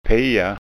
SKÅNSKA UTTAL